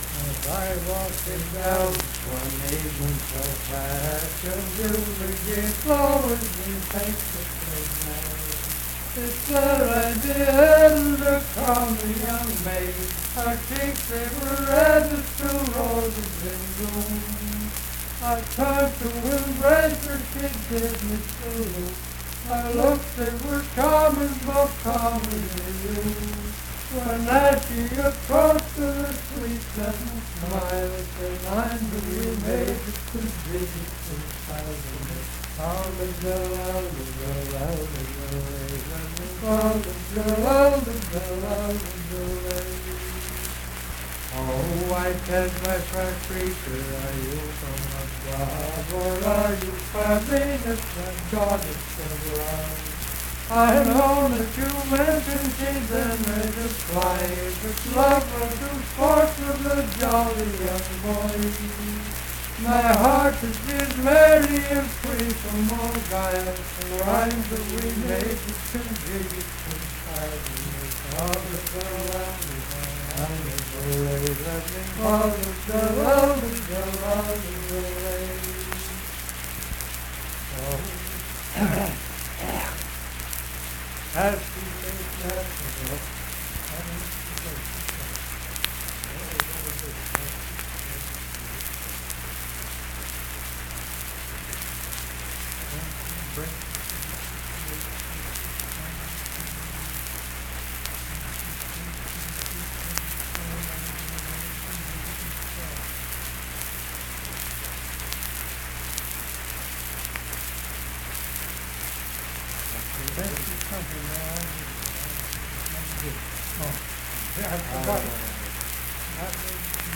Unaccompanied vocal music
Bawdy Songs
Voice (sung)
Nicholas County (W. Va.), Richwood (W. Va.)